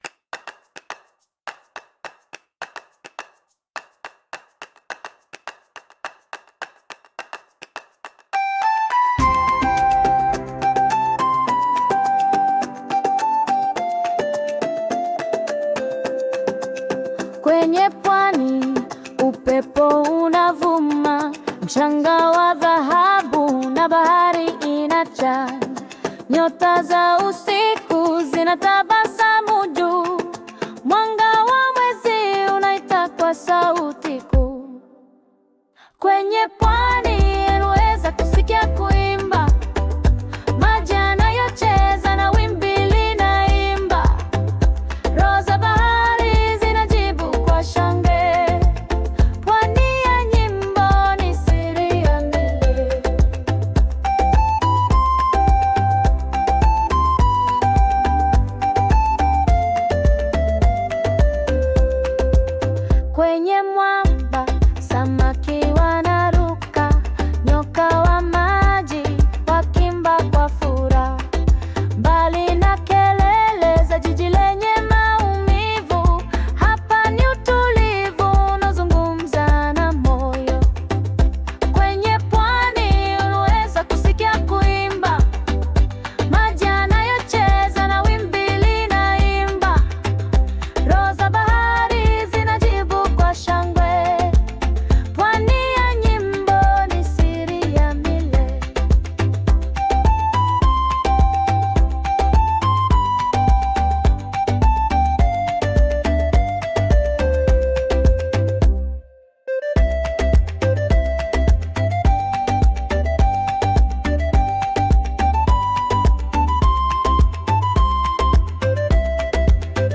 'Pwani Ya Nyimbo' [Wybrzeże Piosenek] stworzona przez AI.